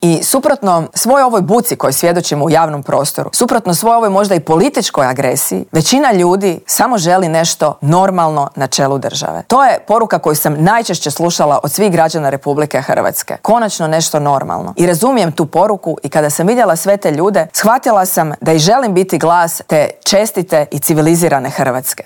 U studiju Media servisa svoj izborni program predstavila nam je nezavisna kandidatkinja Marija Selak Raspudić: "Nismo osuđeni na sukob Milanovića i Plenkovića, većina ljudi samo želi normalne ljude na čelu države."